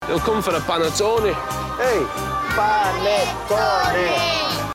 The TV face of the Morrisons supermarket chain is retired cricketer Freddie Flintoff, whose Lancashire accent fits the store’s down-to-earth North-of-England image.
At which point he’s interrupted by Italian chef Aldo Zilli and a chorus of children, correcting his pronunciation with the chant “pa–net–to–ne!”
His short and affricated [ts] differs from the original’s long, unaspirated [tt], and he has un-Italian vowel reduction in the second syllable.  But otherwise he’s pretty close: little aspiration on the [p], a central [a], a monophthongal [oo] and a final middish monophthong [ɪ/e]. Here it is, just for fun, with a digitally improved [tt]: